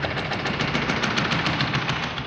Index of /musicradar/rhythmic-inspiration-samples/105bpm
RI_DelayStack_105-01.wav